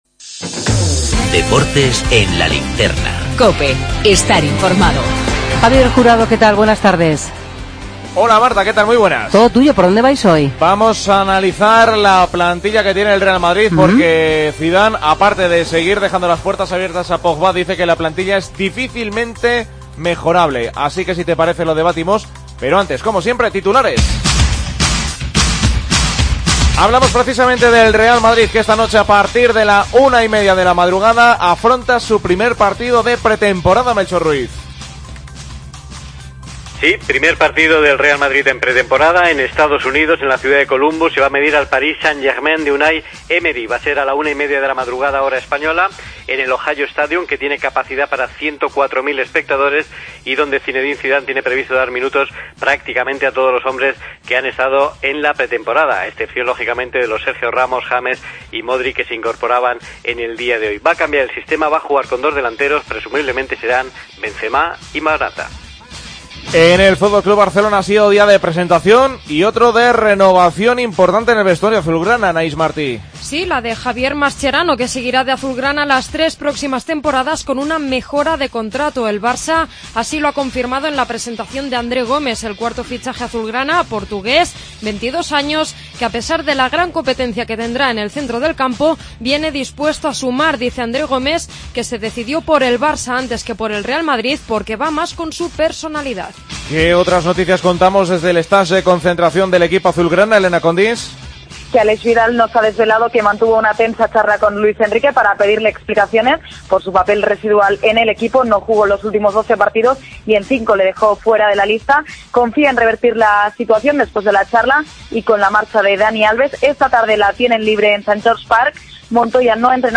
El debate